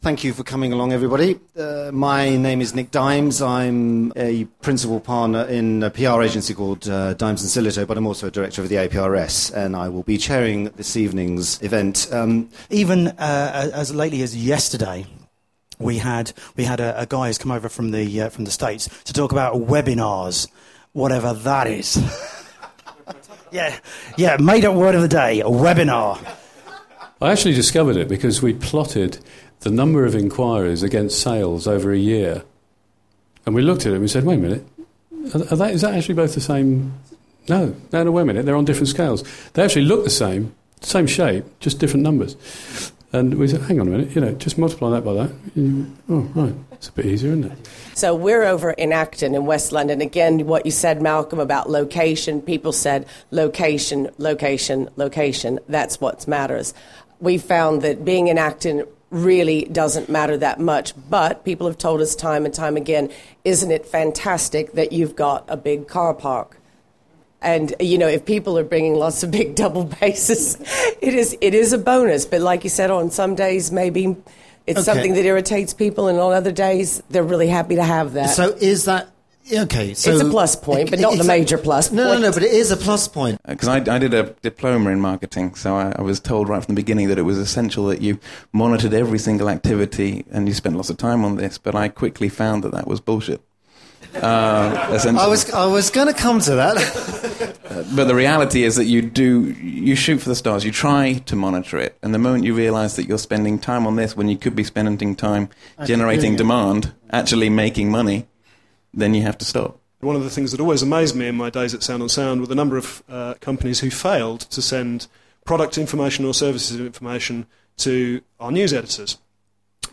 upstairs at The Bath House,
Our expert panel consisted of